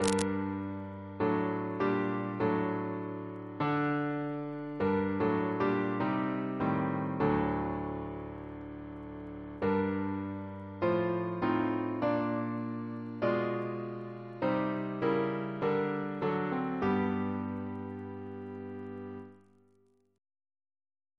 Double chant in G minor Composer: Chris Biemesderfer (b.1958) Note: for Psalm 148